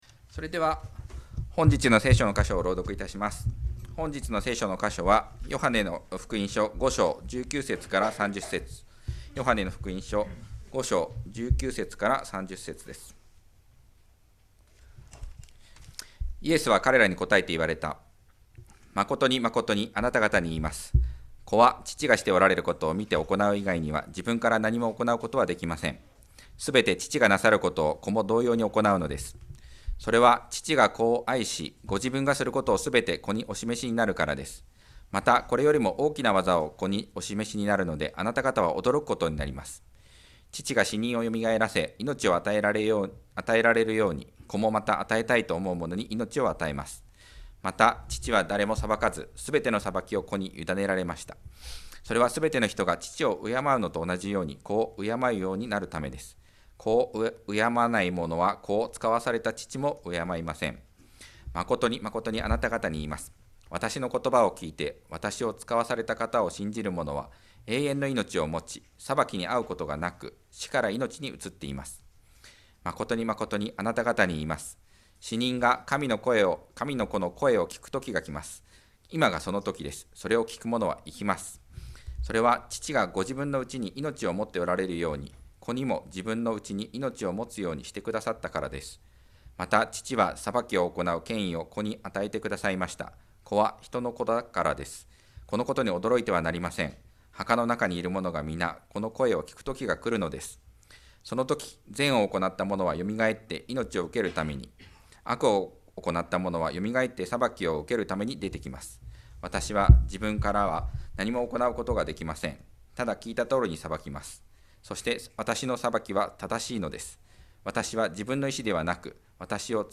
2025年5月18日礼拝 説教 「神の子、安息の主、人の子」 – 海浜幕張めぐみ教会 – Kaihin Makuhari Grace Church